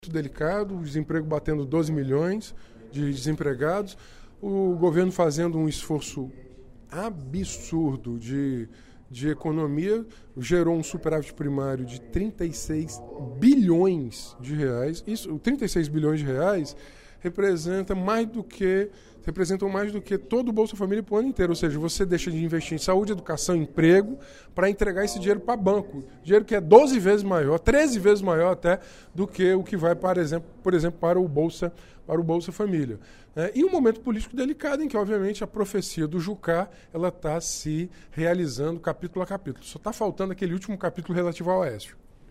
O deputado Renato Roseno (Psol) avaliou, durante o primeiro expediente da sessão plenária desta quinta-feira (02/03), o atual cenário nacional.